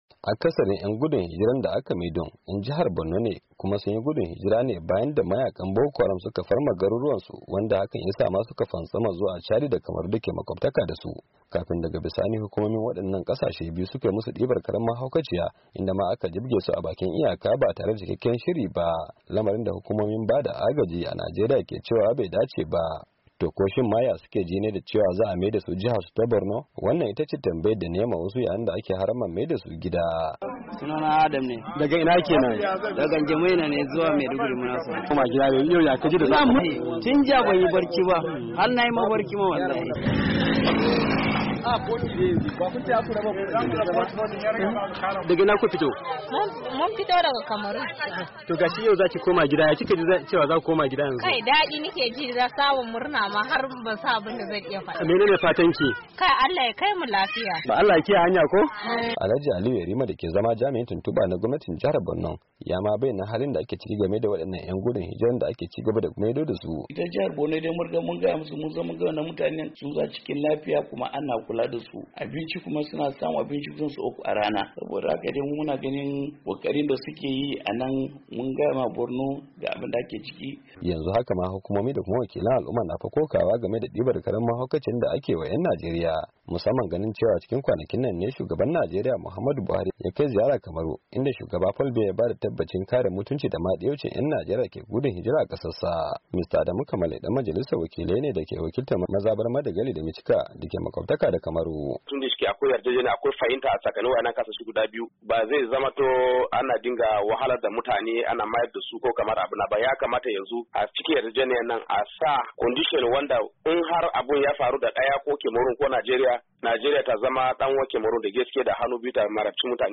Ga cikakken rahoton